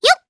Estelle-Vox_Jump_jp.wav